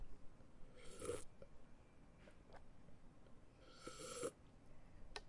OWI SFX声音 " 房屋钥匙
描述：咬一口苹果。
Tag: 水果 苹果 OWI 紧缩